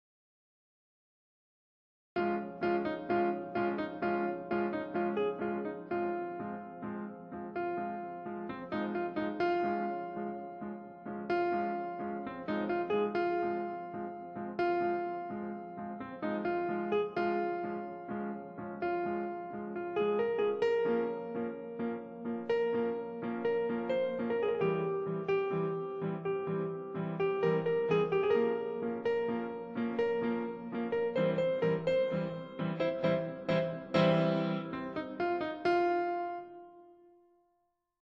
Eigene Version